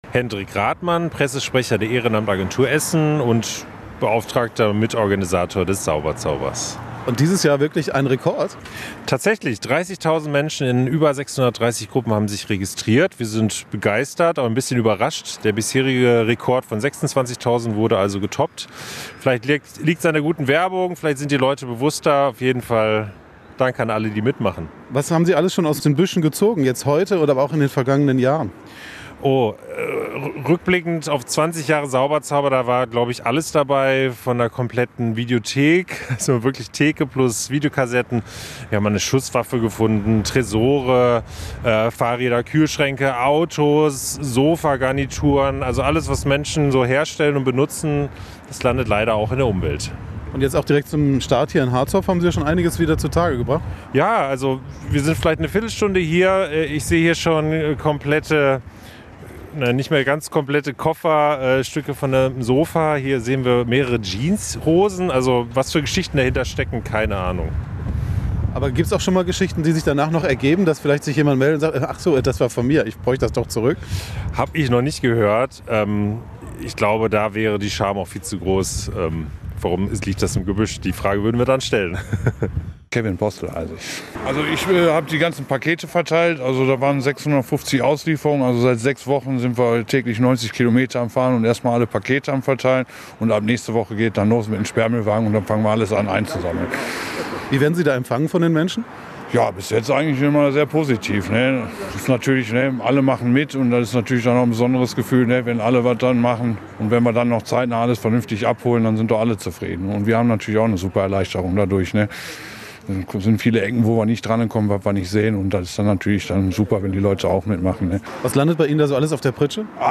im Gespräch mit Veranstalter, EBE und Sammlern